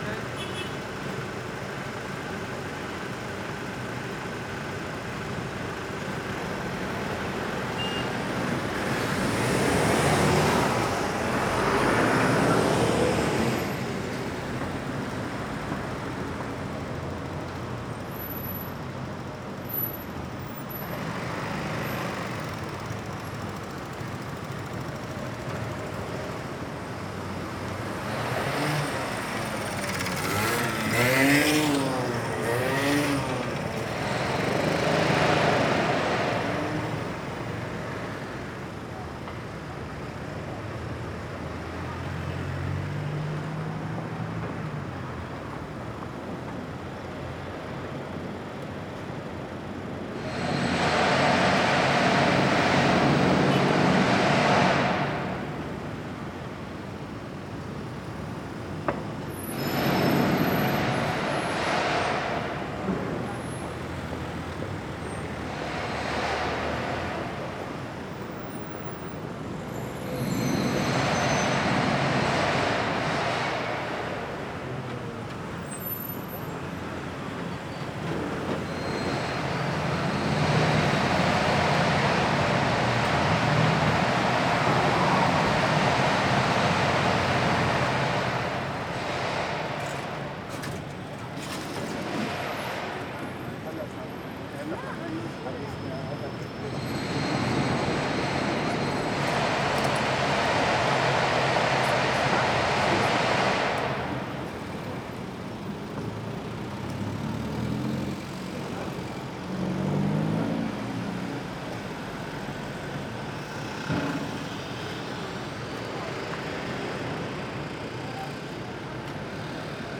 Levallois-Perret crossroads, near the Louise Michel metro station. Traffic and works.